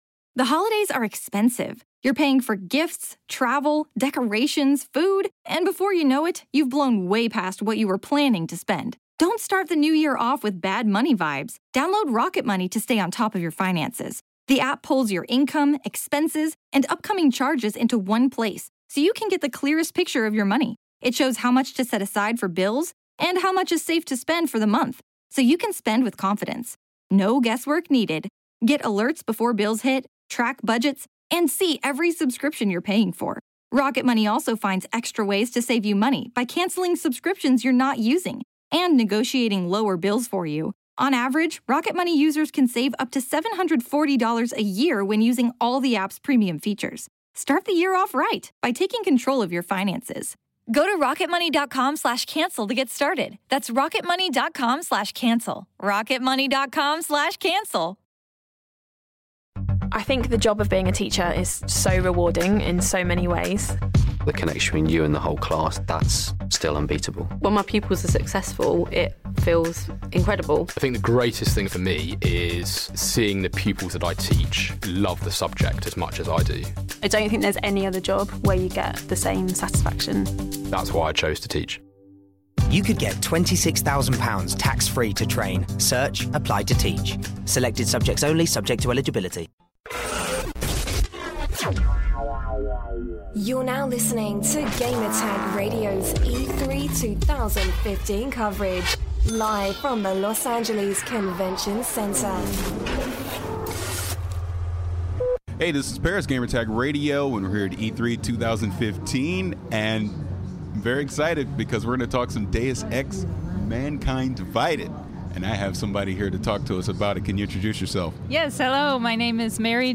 E3 2015: Deus Ex Interview